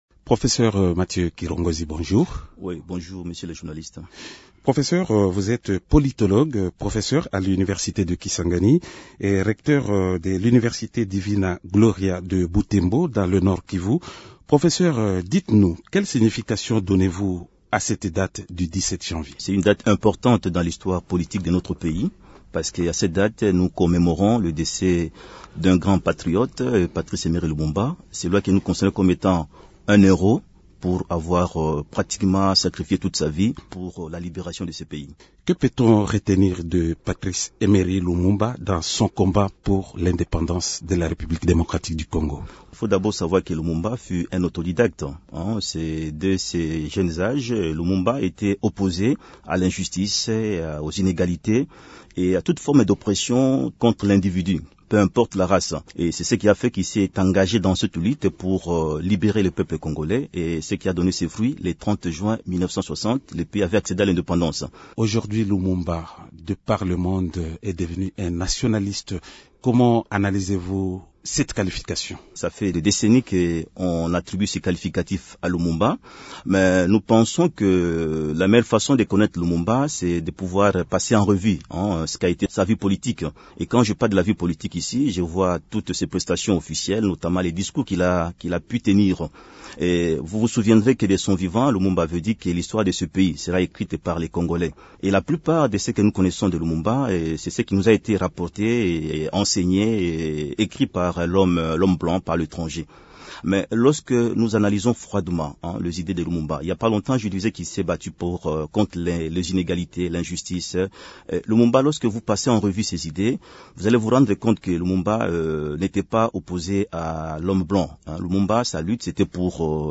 Il s’entretient avec